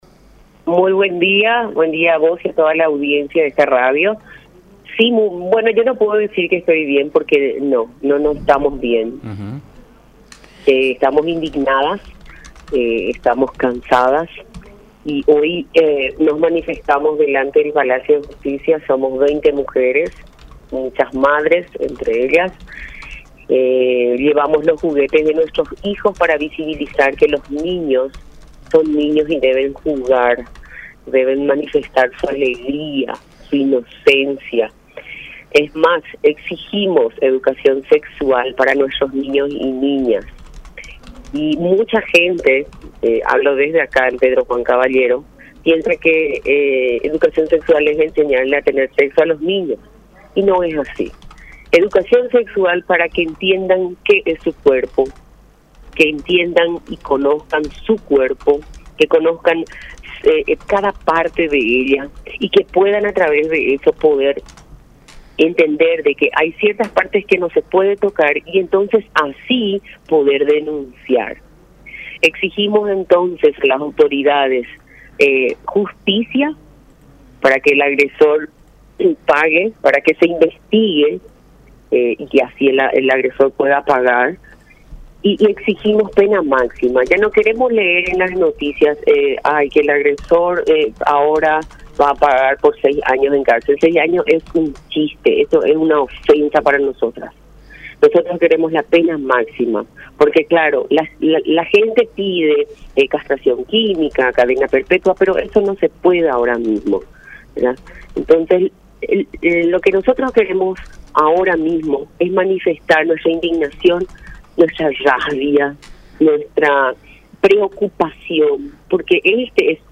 La Unión R800 AM